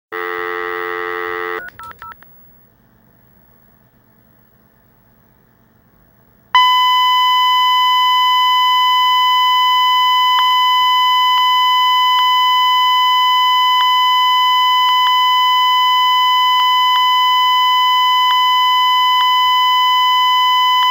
For some reason, using G722 codec caused clicking to be added to the call, but this does not happen with G711.
Clicking sounds "random" to the ear and does not have a steady rhthym.
01-Tone_-_Custom.mp3